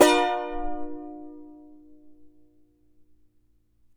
CHAR C MJ  D.wav